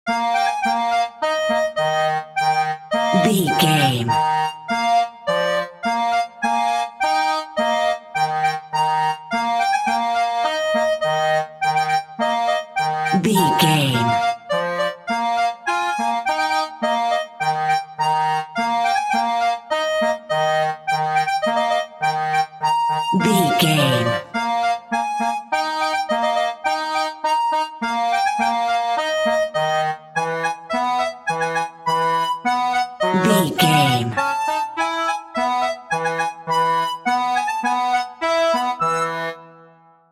Ionian/Major
E♭
nursery rhymes
childrens music